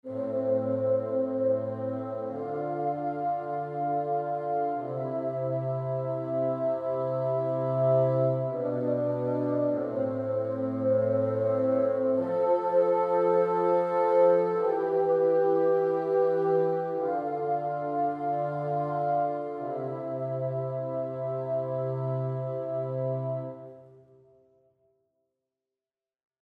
Die Fagotte im Legato-Duett, Sustain-Artikulation: